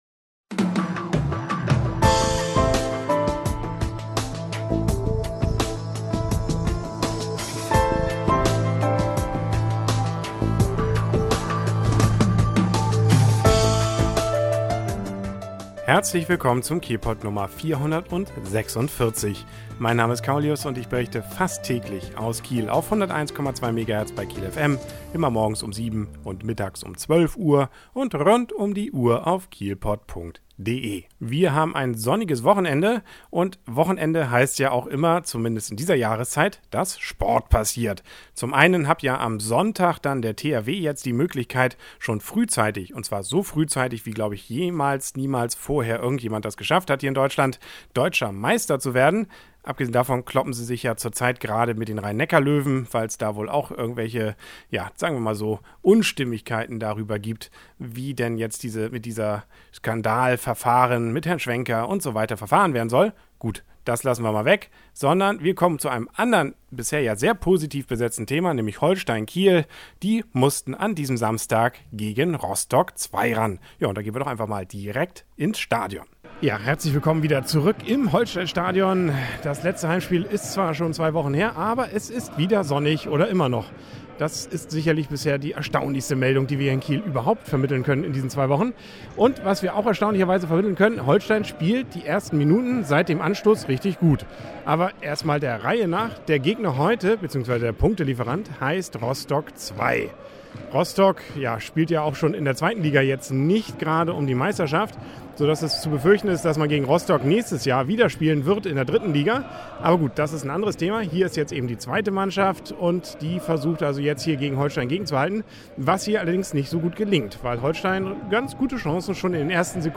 Livebericht vom Spiel Holstein Kiel gegen Hansa Rostock II mit Interviews von Spielern beider Mannschaften.